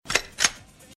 • GUN COCKING.mp3
gun_cocking_mz1.wav